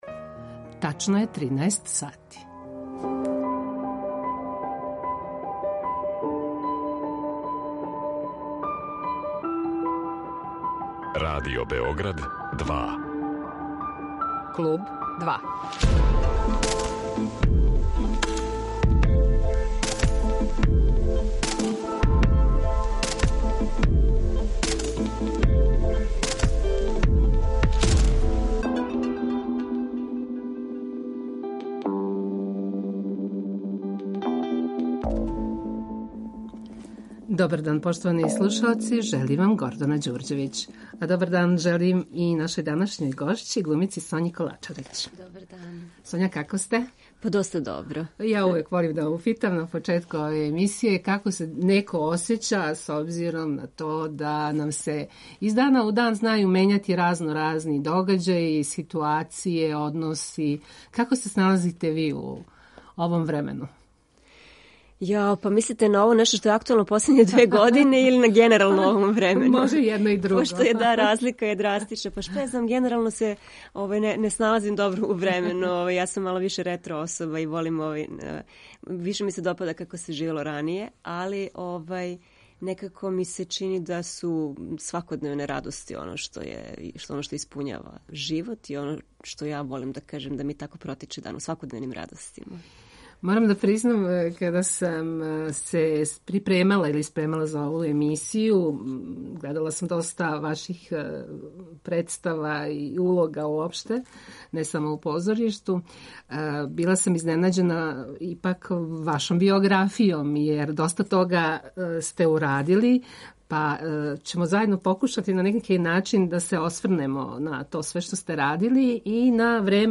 Гост Клуба 2 је глумица Соња Колачарић.